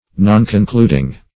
Nonconcluding \Non`con*clud"ing\, a. Not concluding.